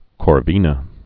(kôr-vēnə)